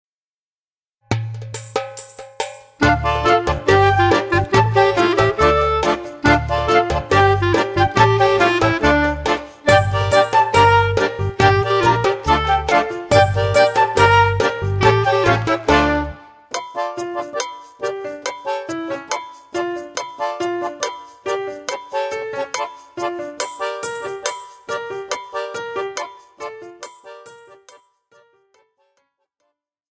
36 pfiffige Lieder in Mundart und Hochdeutsch